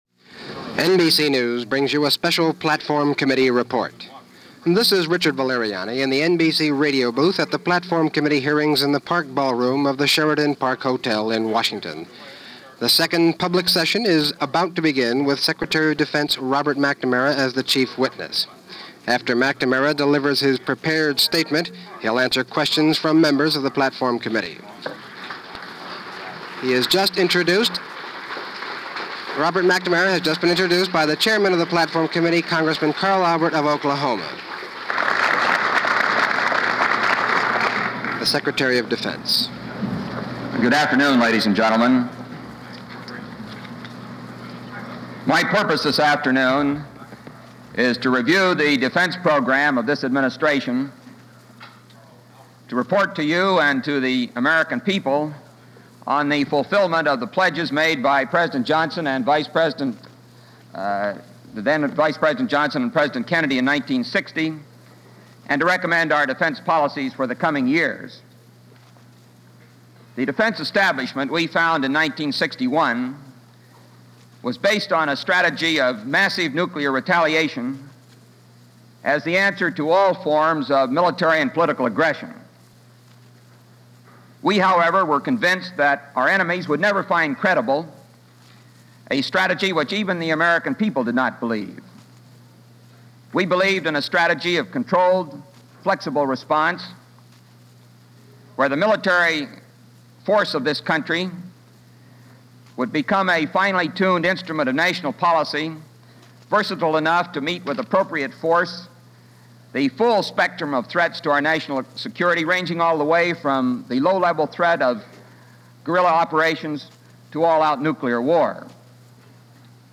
Secretary of Defense Robert McNamara addresses The Democractic Platform Committee - 1964 - Past Daily Reference Room: Vietnam War